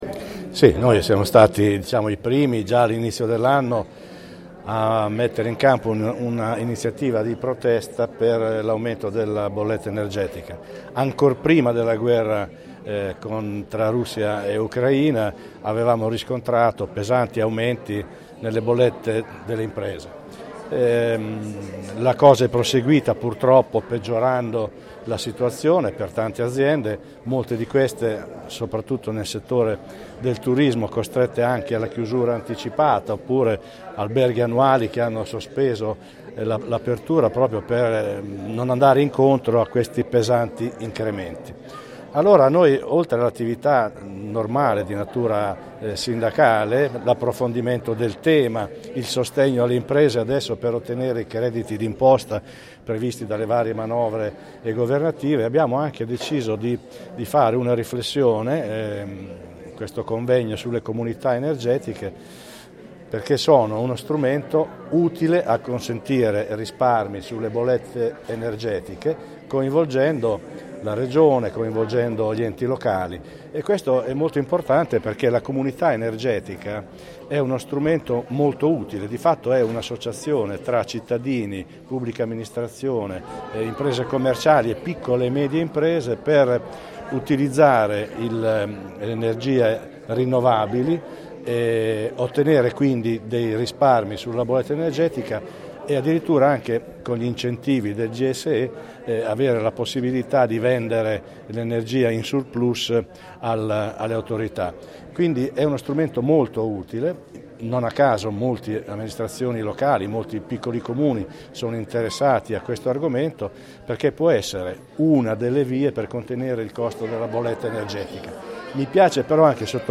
Interessante convegno promosso da Confcommercio Marche/Nord sulle comunità energetiche, sugli argomenti di sviluppo di energie pulite e sostenibili, vero fulcro da cui ripartire anche in chiave economica e di risparmio.